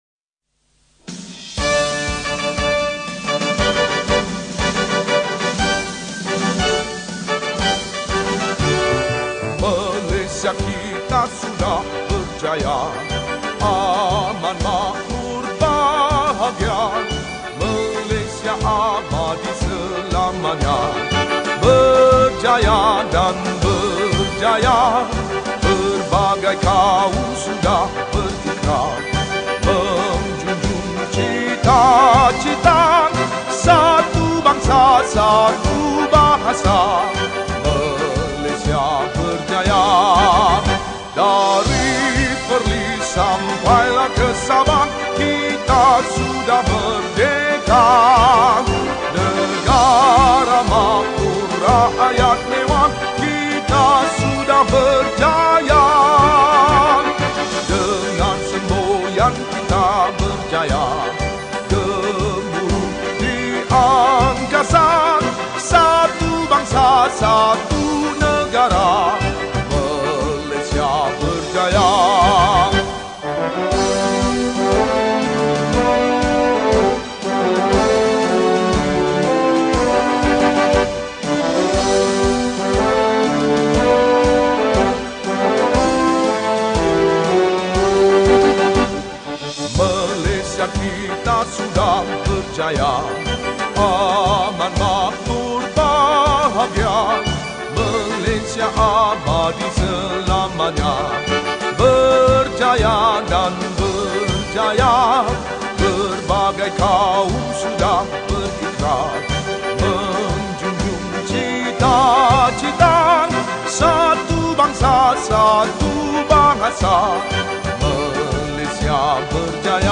Malay Songs , Patriotic Songs